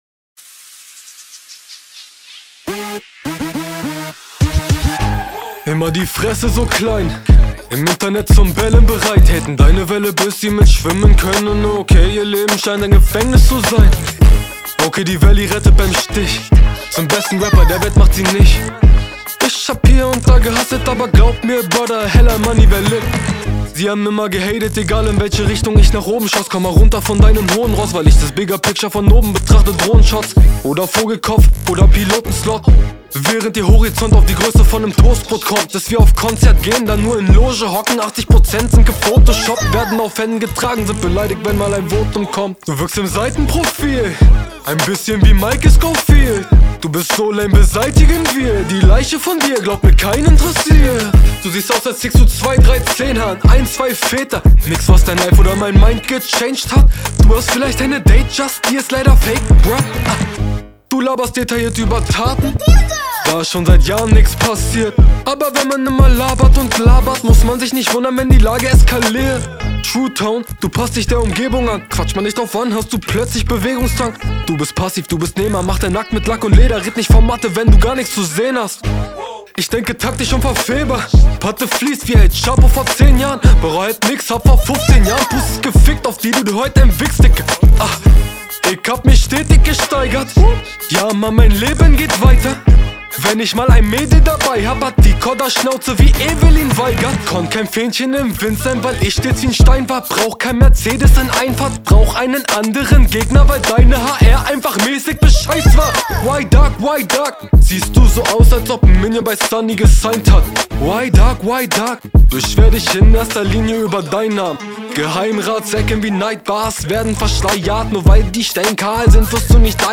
Mir ist das etwas zu Laidback gerappt für den Beat, dafür sind die schnelleren Stellen …